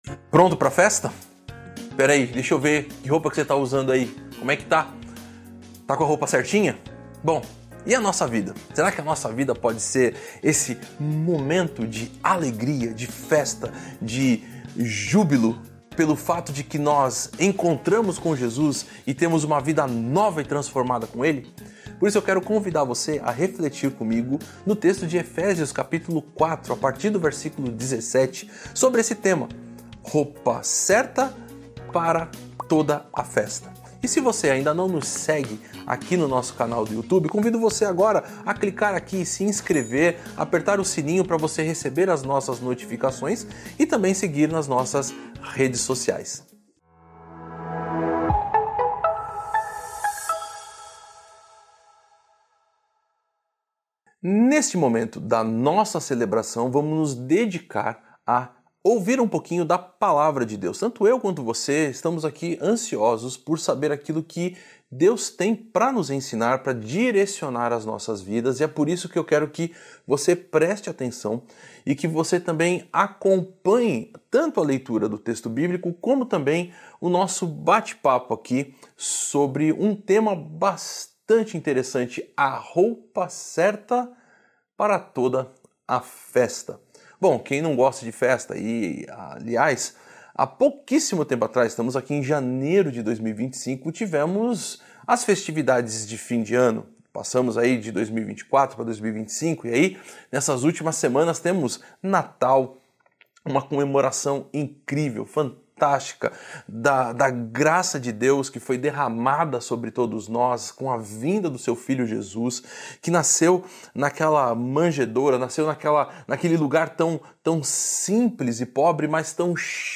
Mensagem
Igreja Batista Nações Unidas